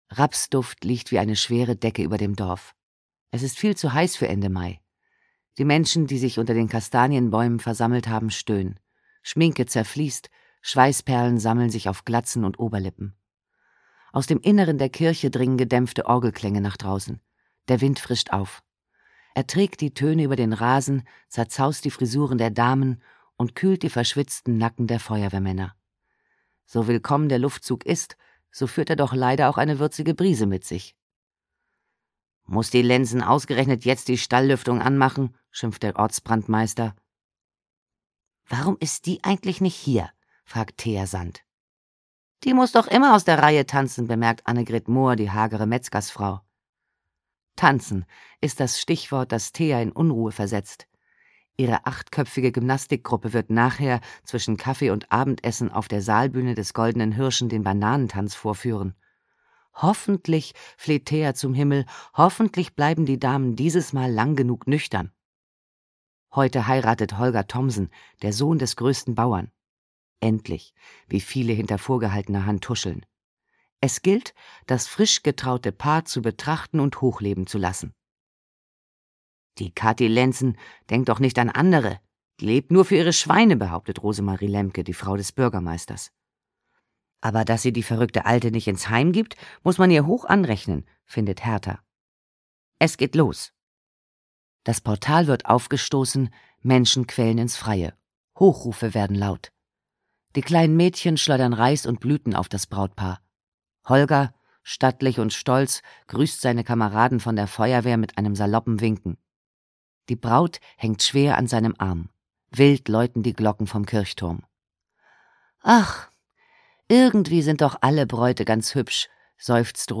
Krimi to go: Mord Sau - Susanne Mischke - Hörbuch